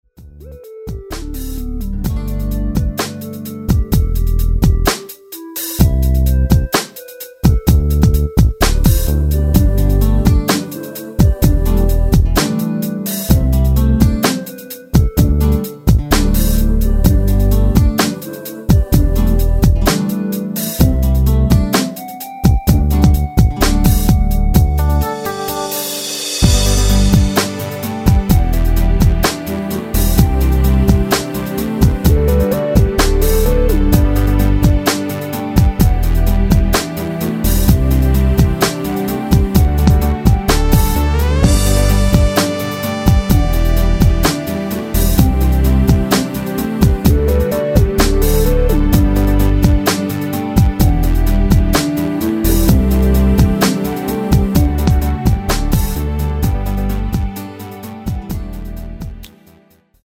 노래방에서 노래를 부르실때 노래 부분에 가이드 멜로디가 따라 나와서
앞부분30초, 뒷부분30초씩 편집해서 올려 드리고 있습니다.
중간에 음이 끈어지고 다시 나오는 이유는